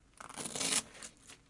描述：扯掉valcrow